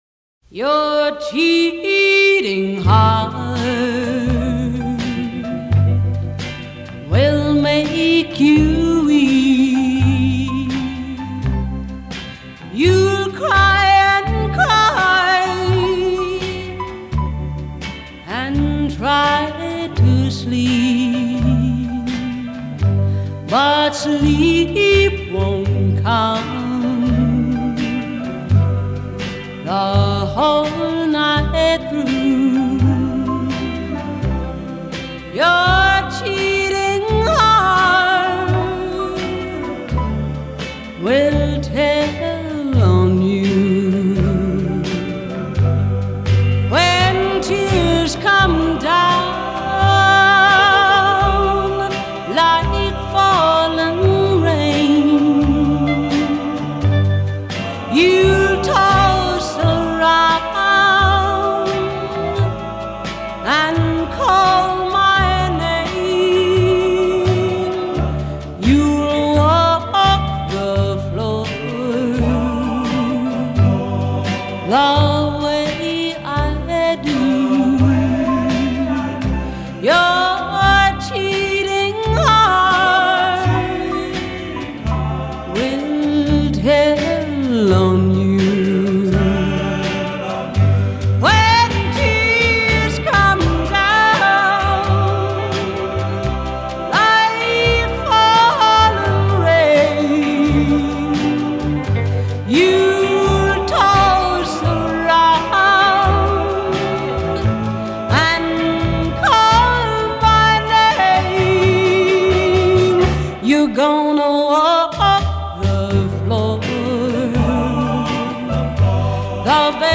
прекрасной певицей
и её весьма приятными и мелодичными композициями.